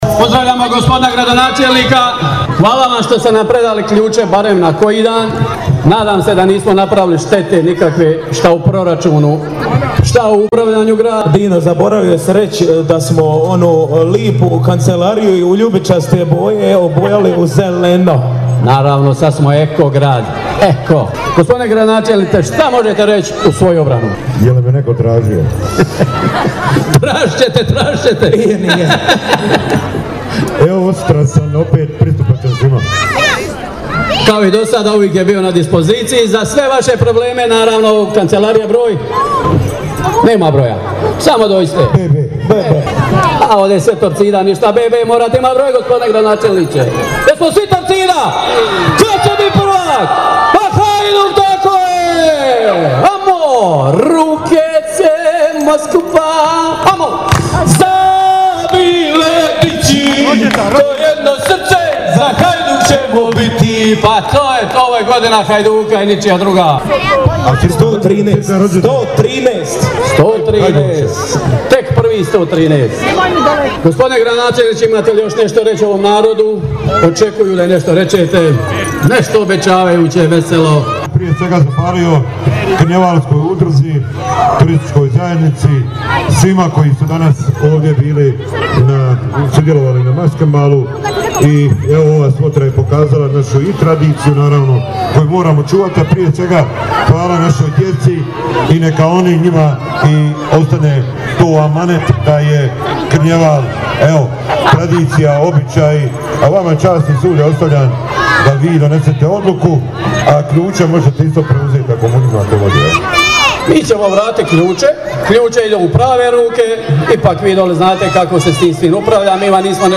Malo predike, zabave, nastupa žonglerice iz Zagreba, vratili su ključe Grada Sinja gradonačelniku Miru Bulju.
predaja-kljuca-grada.mp3